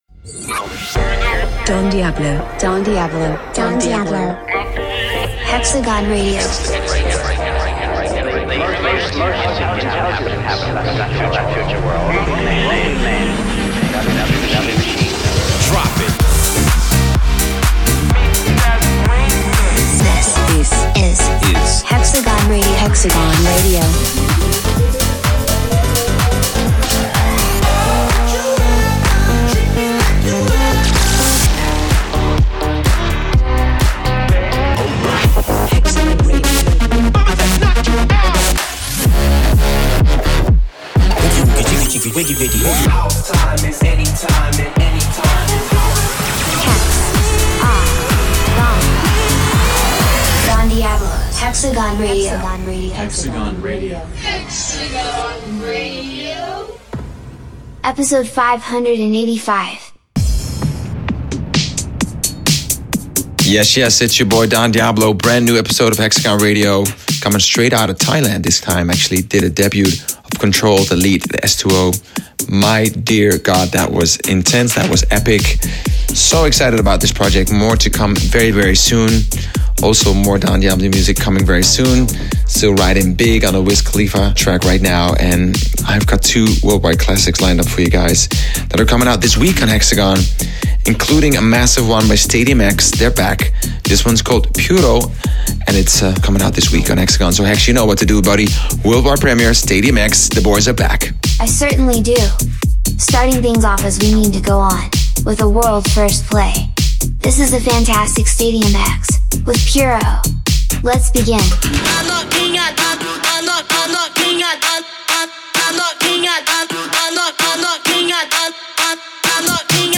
Guest Mix